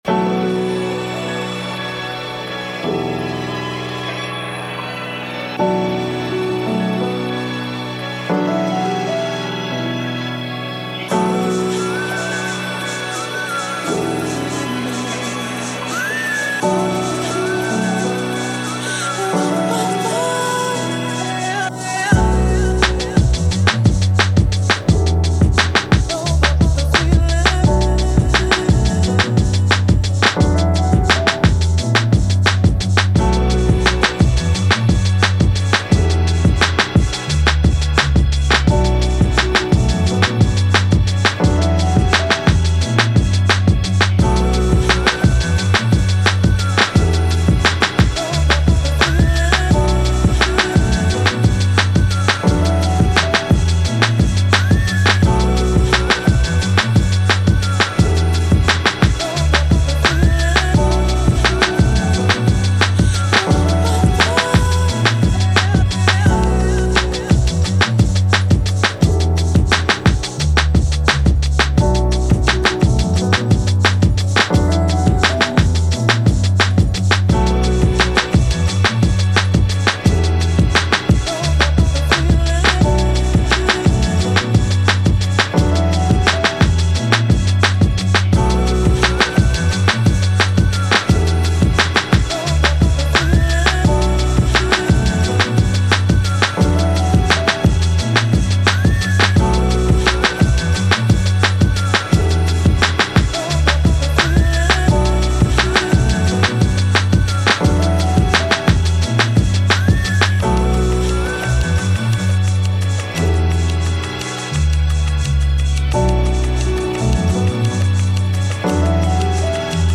Elektronauts Hiphop Beat Battle #8 - Back to 92 edition
Here’s my other version I didn’t finish. Weirdly I had the piano, drums and bass melody from a track from ages ago I never finished (pattern forming) and had an inkling it would all be in tune (sort of) when I started playing around with the samples for the battle.